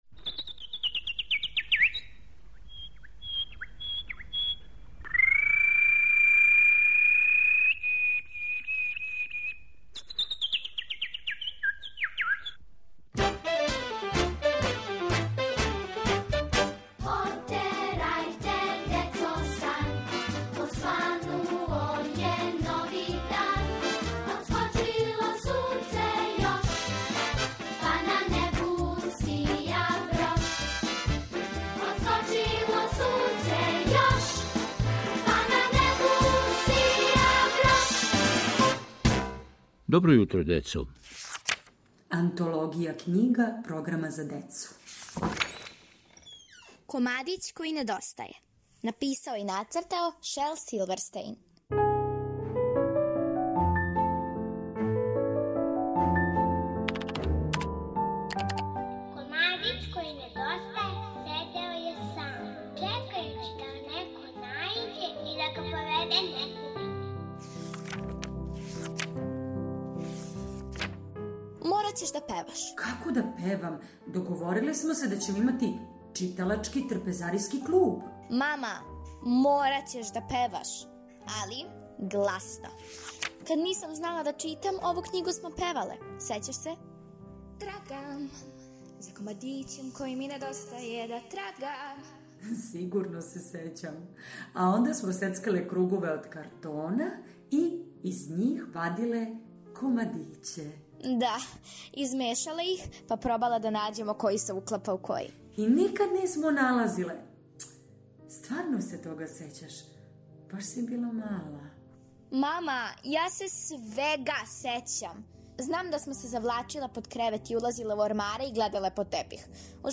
У новом серијалу "Антологија књига Програма за децу", у форми радио игре, послушајте причу о књизи Шела Силверстејна "Комадић који недостаје".